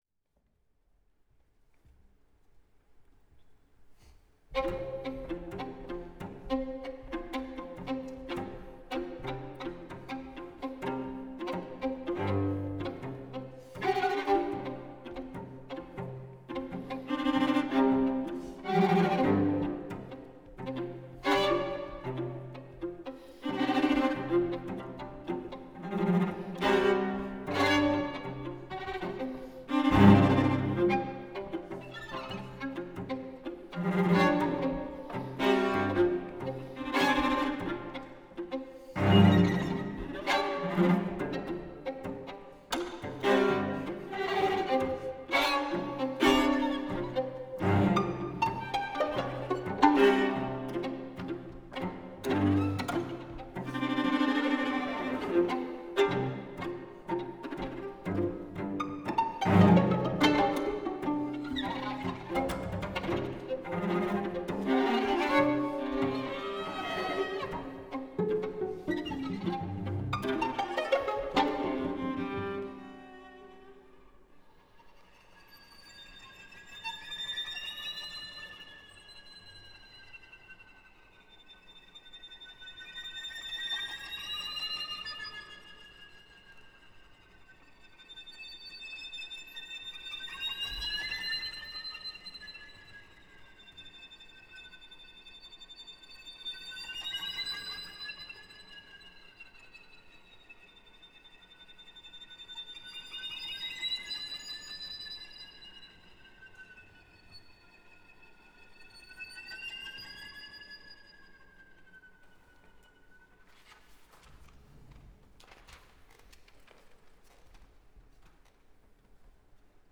violin
viola
cello
for string trio